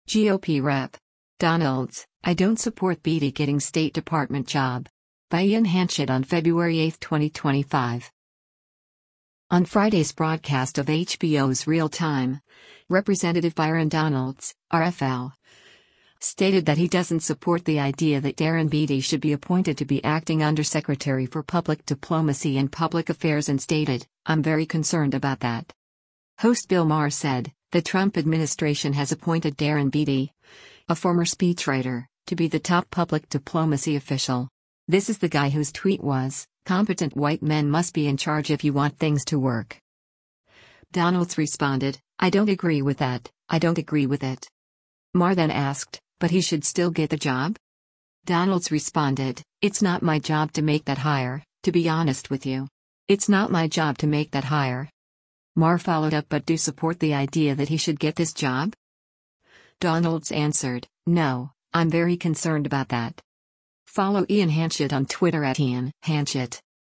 On Friday’s broadcast of HBO’s “Real Time,” Rep. Byron Donalds (R-FL) stated that he doesn’t support the idea that Darren Beattie should be appointed to be acting Undersecretary for Public Diplomacy and Public Affairs and stated, “I’m very concerned about that.”